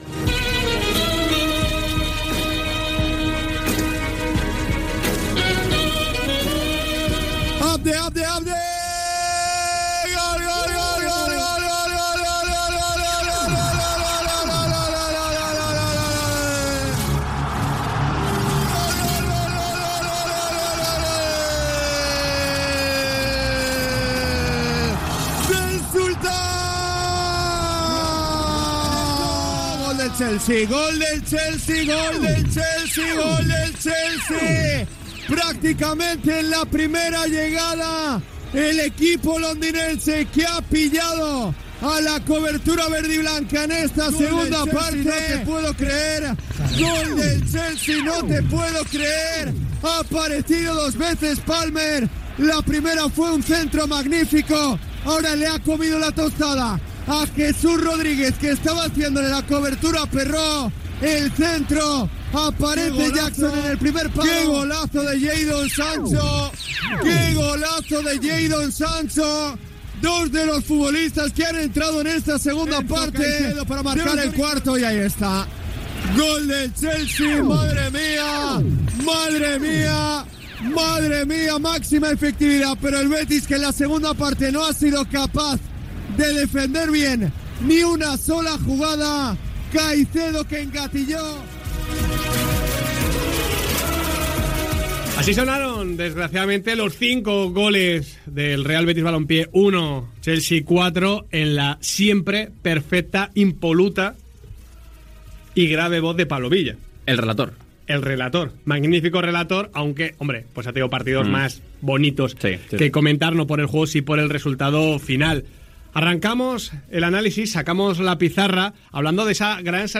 Començament de la valoració tècnica del partit. Gènere radiofònic Esportiu